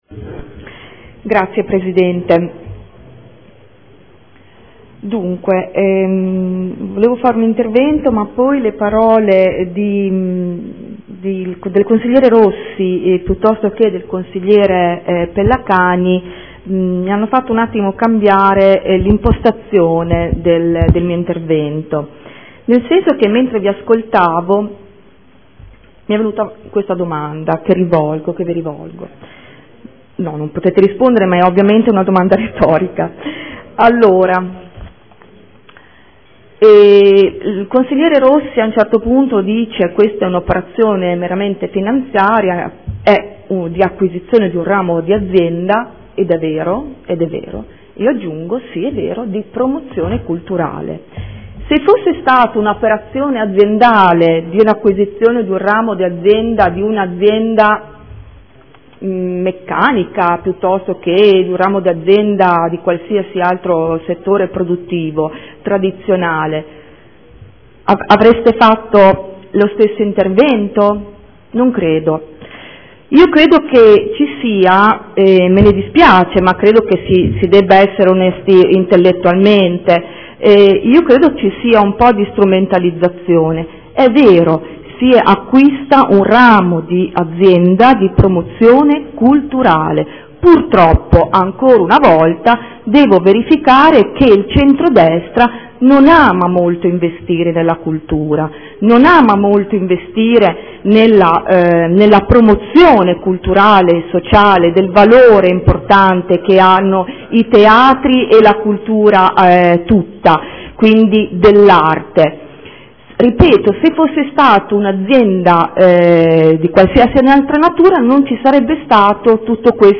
Ingrid Caporioni — Sito Audio Consiglio Comunale